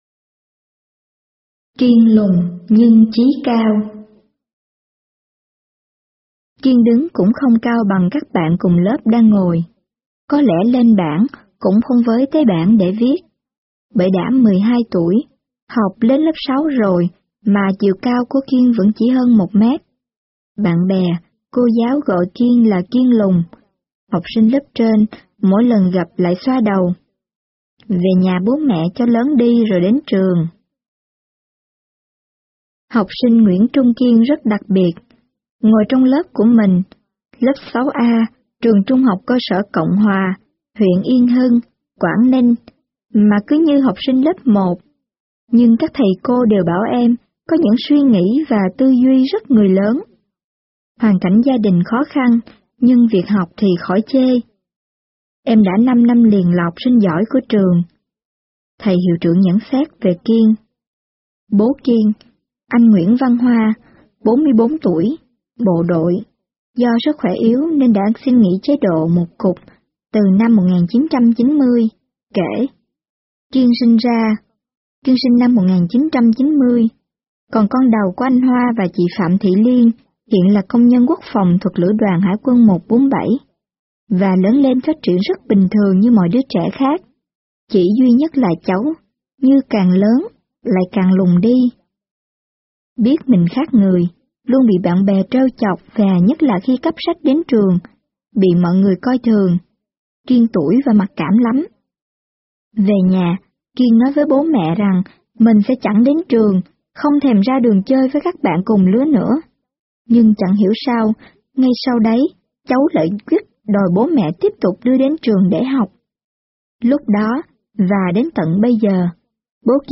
Sách nói | tuy lùn mà chí cao